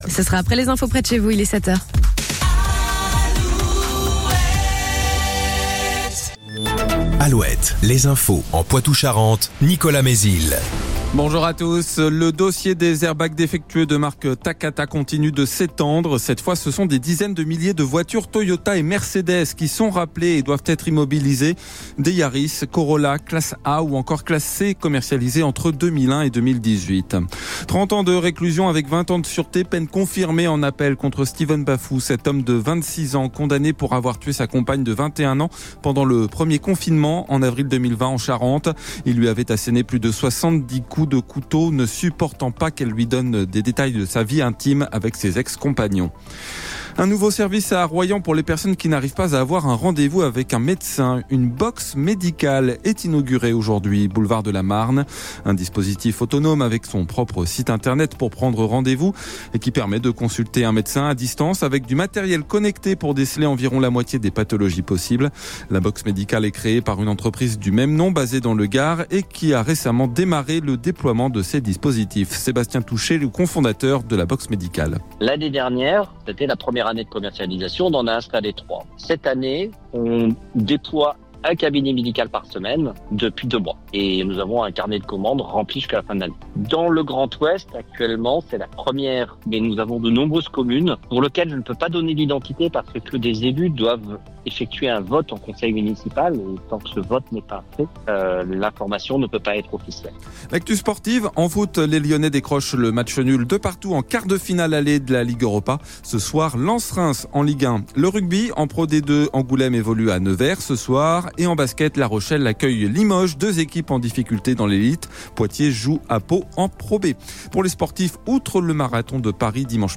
Inauguration Box Médicale à Royan – Flash info dans la matinale radio Alouette